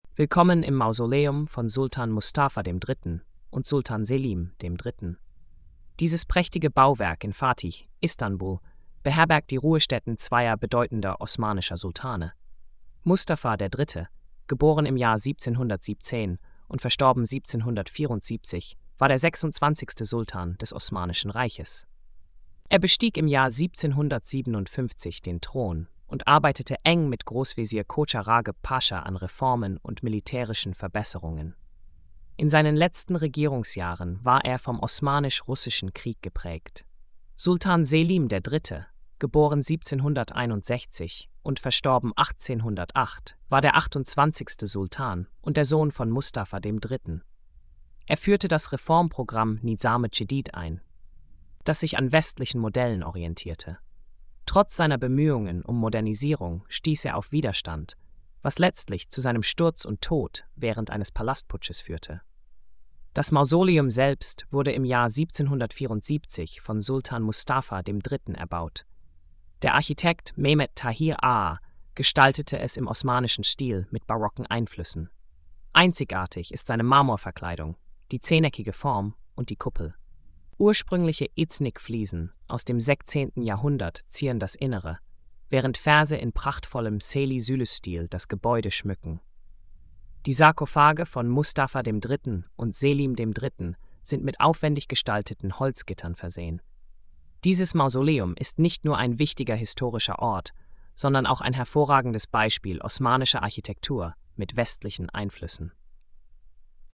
Audio Erzählung: